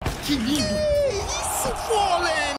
gaules que isso fallen Meme Sound Effect